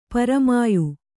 ♪ paramāyu